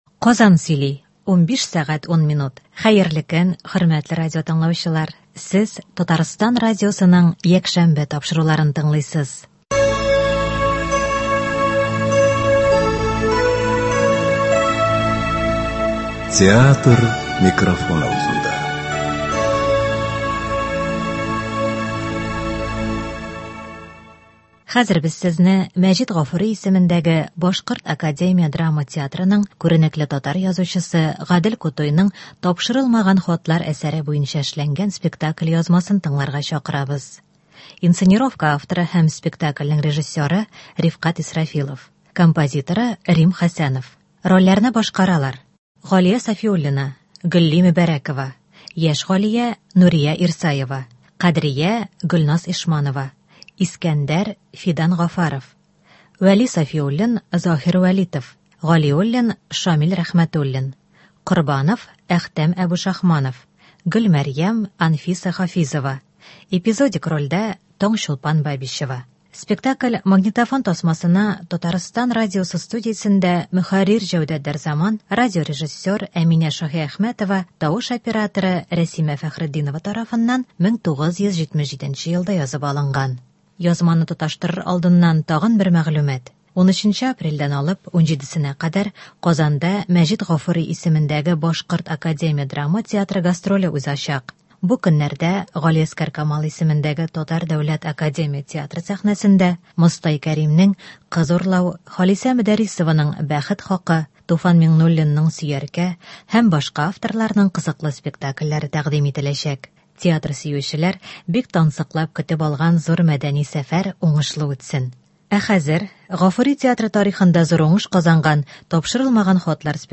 Мәҗит Гафури ис.БДАДТ спектакленең радиоварианты.